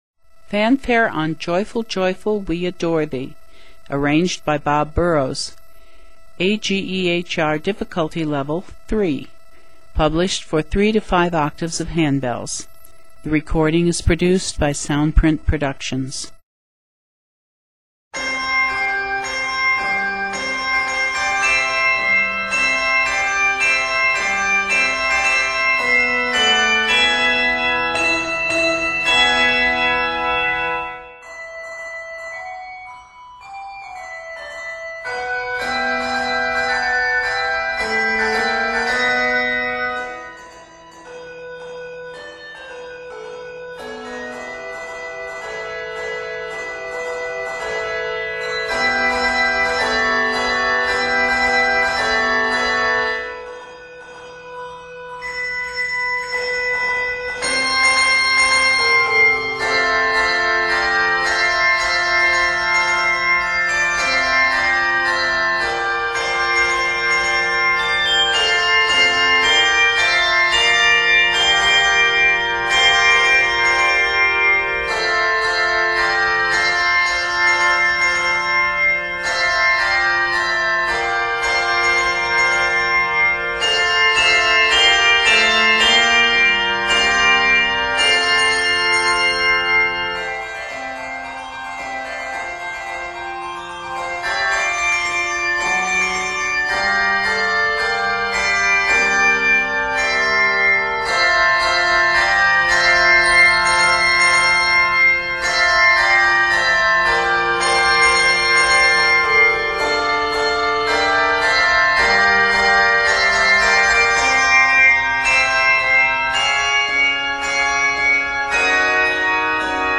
Octaves: 5